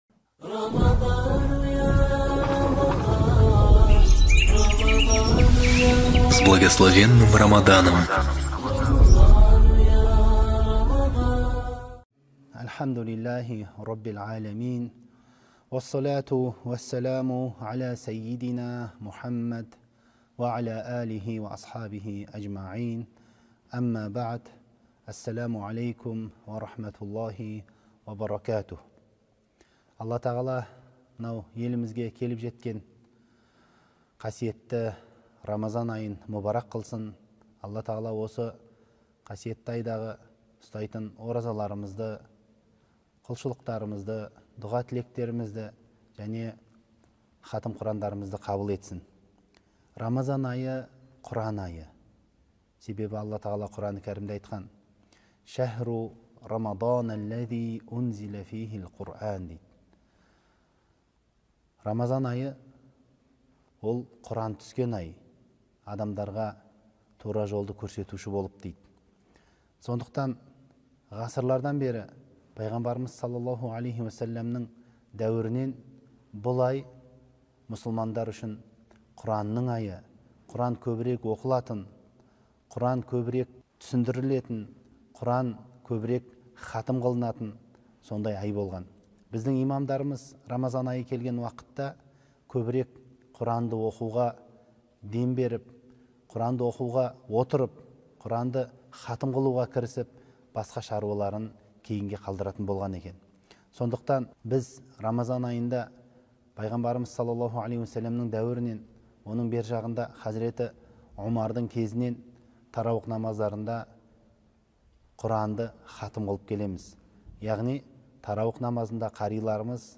Хатм Корана читаемый в Центральной мечети г.Алматы в период Священного месяца Рамадан.